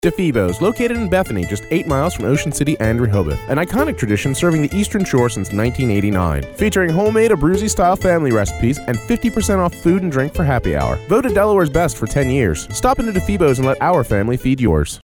Having a discrete soundbooth and professional setup, let Modern Eye Media work with you to create high quality productions.
MXL 770 Condenser Microphone, Focusrite Scarlett 2i4 Audio Interface, DBX 286S Preamp/Processor, Adobe Audition Creative Cloud.
Difebo’s Restaurant Finished Radio Ad